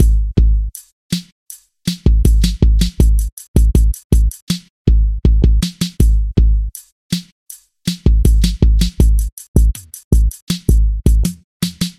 艰难的陷阱节拍
标签： 160 bpm Trap Loops Drum Loops 2.03 MB wav Key : Unknown
声道立体声